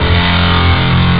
la chitarra "elettrica", che è piatta e utilizza l'elettricità per amplificare i suoni, essendo collegata a un amplificatore tramite un cavo.
Chitarra elettrica
guitar2.wav